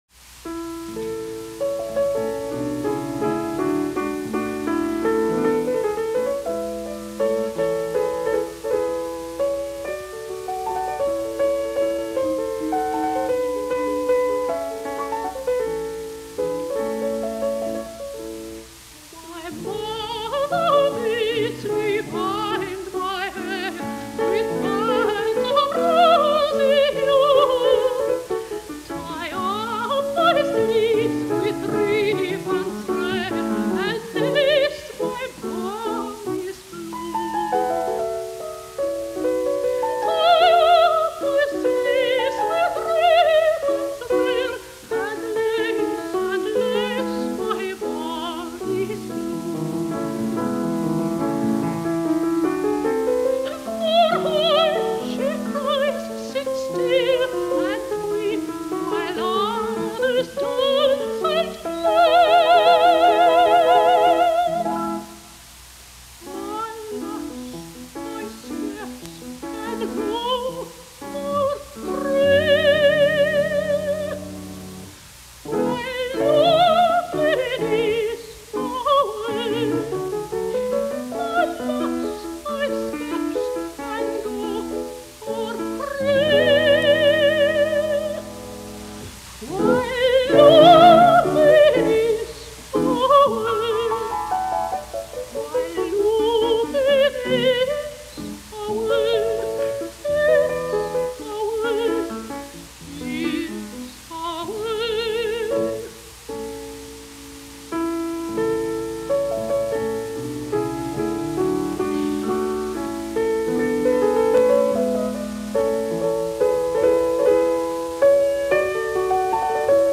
My First Music: “My Mother Bids Me Bind My Hair” by Joseph Haydn, Sung by Joan Sutherland with Richard Bonynge at the Piano, 1970
Smile as you will at this mincing little ditty but it got me a medal at the Tri-State Vocal Competition of 1969 when I was fourteen.
my-mother-bids-me-bind-my-hair-joan-sutherland.mp3